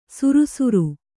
♪ surusuru